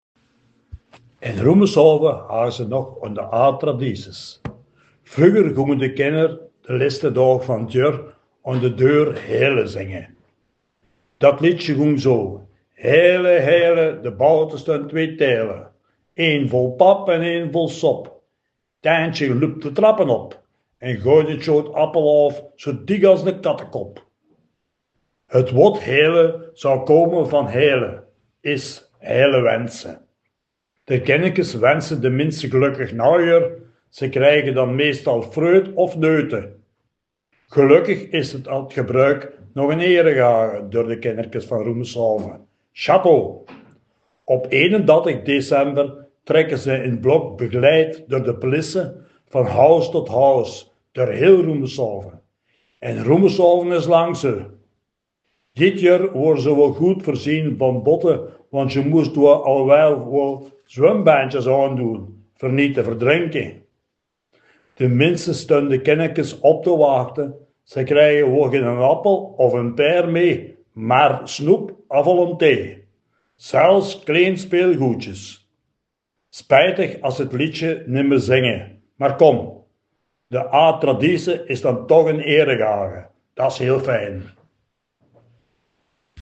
Veldeke Belgisch Limburg | Belgisch-Limburgse dialecten